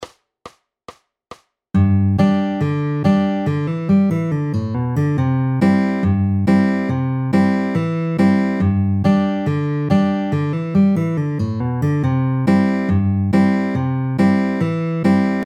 Country Lick über G/C-Dur
CountryLickC-Dur.mp3